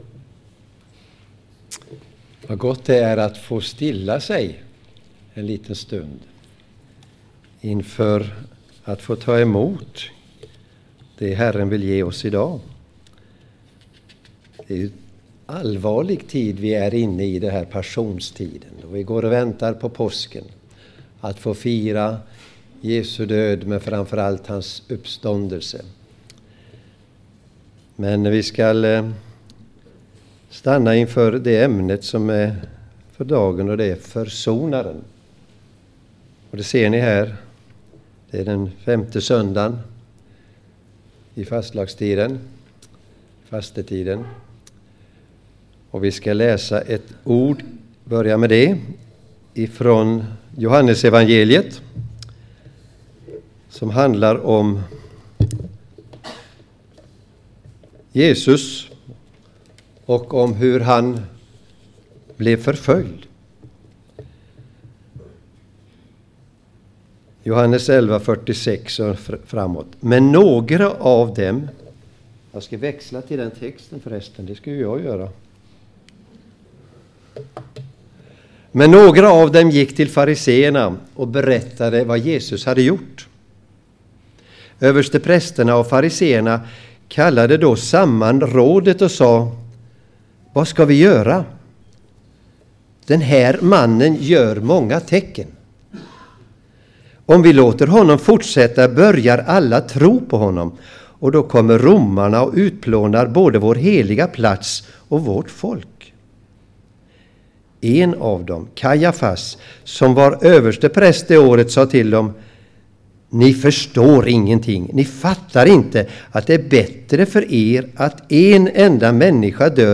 Skandinaviska Turistkyrkan Costa del Sol